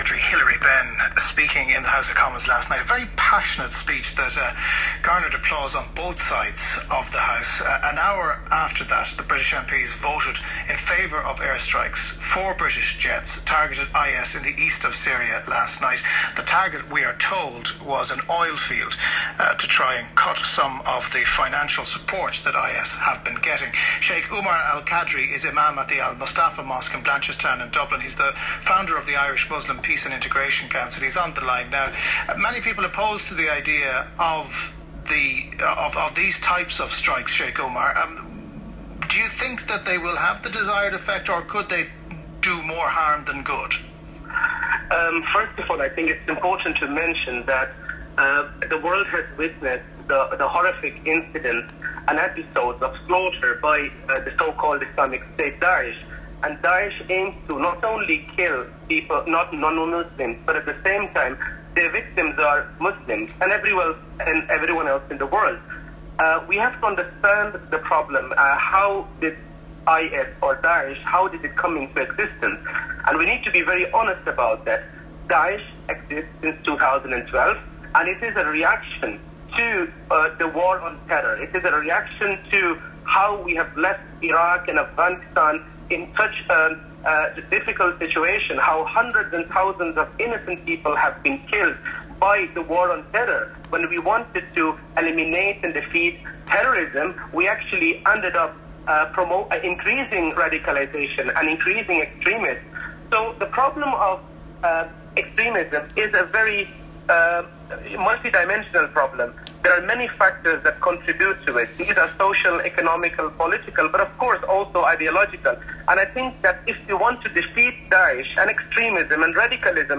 Jean Pierre Thebault, French Ambassador to Ireland, Regina Doherty, Fine Gael TD Meath East